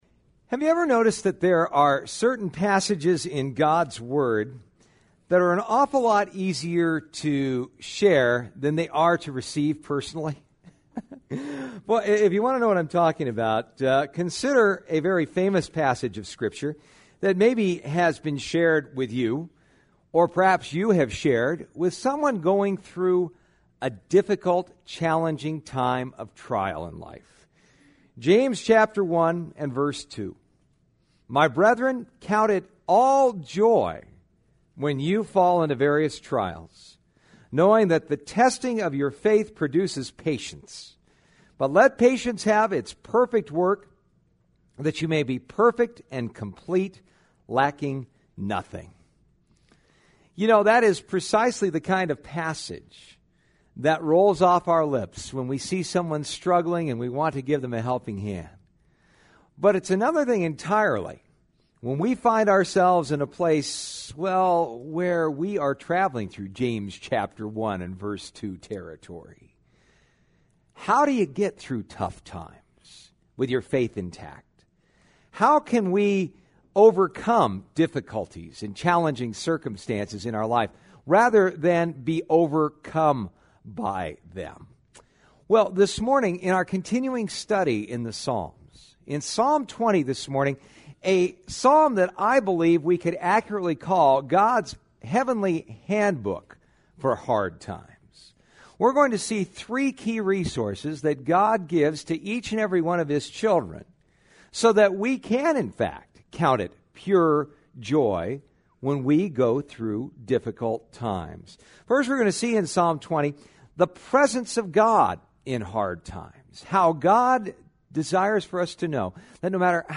Psalm 20 Service Type: Sunday Morning « When God Touches Your Life